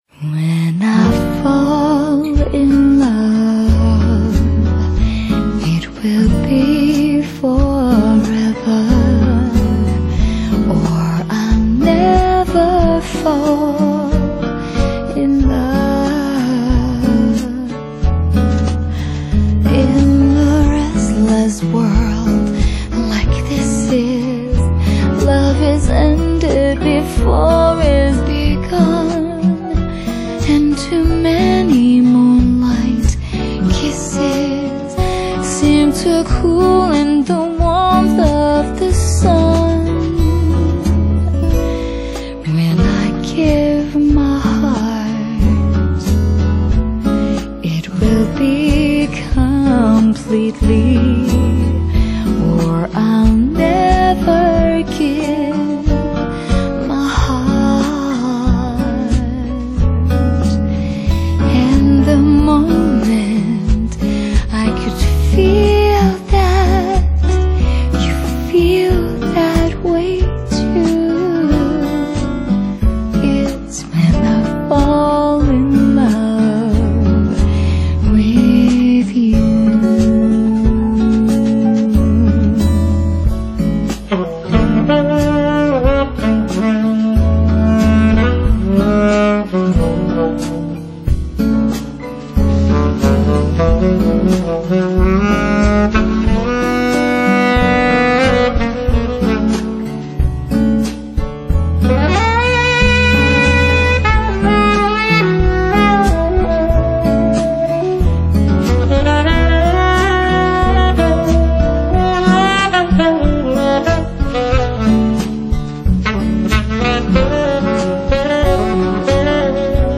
【泰國女爵士歌手】
| 320kbps, 85MB | Jazz, Vocal | 2010